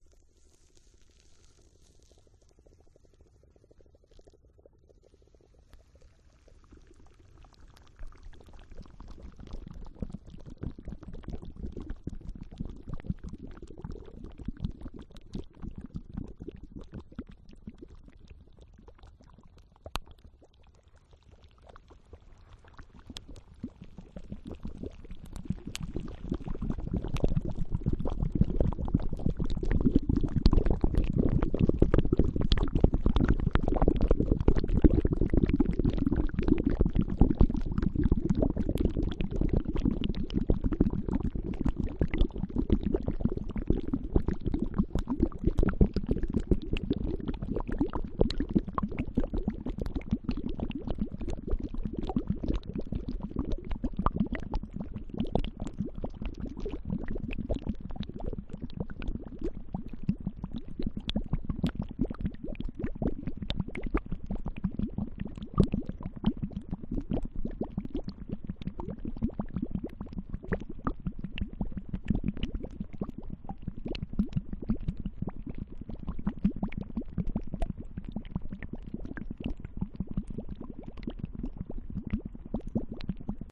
Звуки химии
Химическая реакция кипящая вода на сухой лед в желе густые пузыри плеск